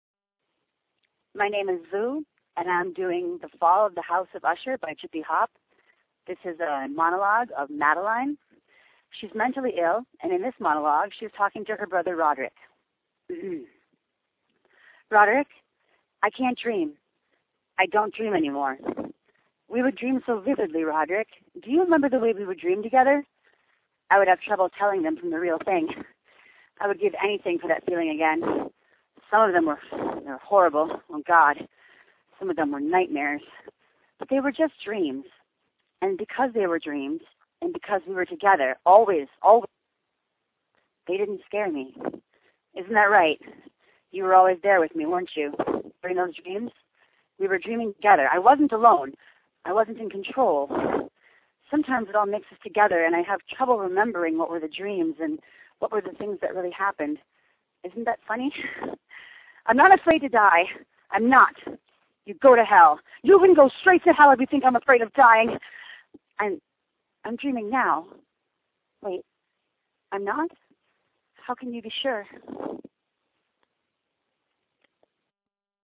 Me, performing Madeline's monologue from The Fall Of The House Of Usher.